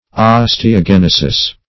Osteogenesis \Os`te*o*gen"e*sis\
osteogenesis.mp3